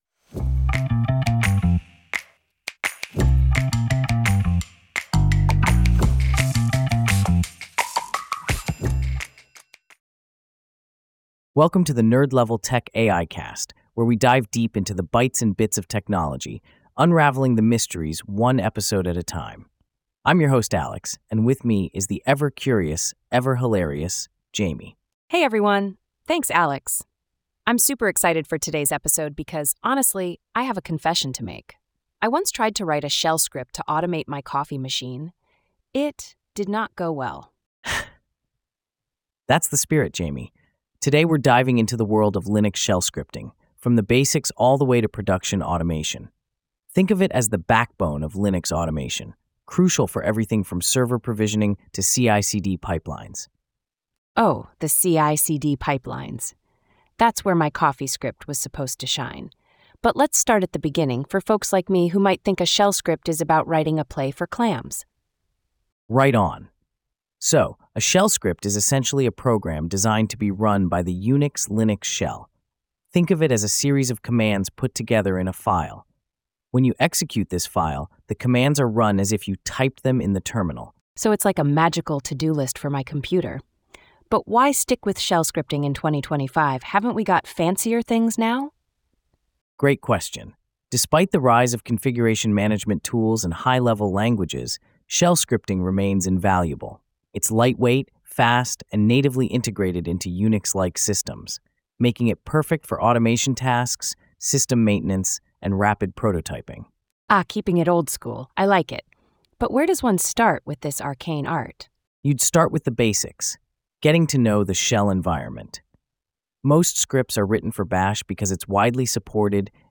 AI-generated discussion